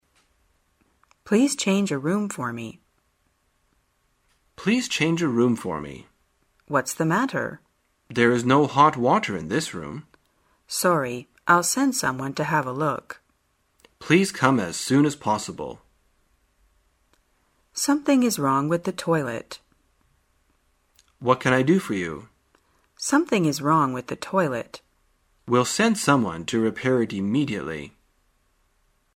在线英语听力室生活口语天天说 第147期:怎样提出要求的听力文件下载,《生活口语天天说》栏目将日常生活中最常用到的口语句型进行收集和重点讲解。真人发音配字幕帮助英语爱好者们练习听力并进行口语跟读。